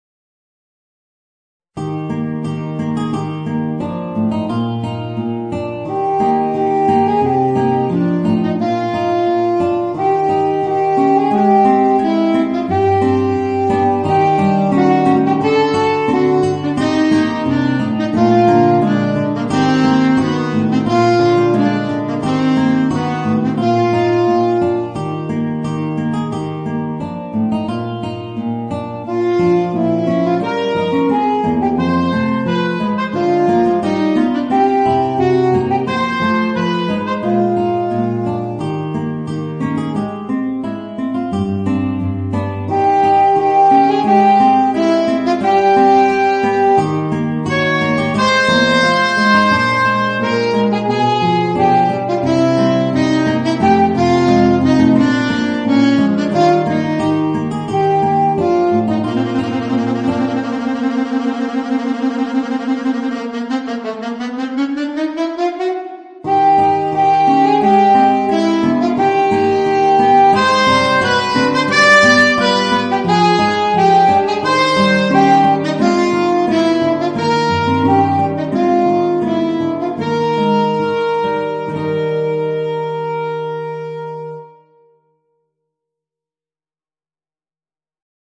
Voicing: Alto Saxophone and Guitar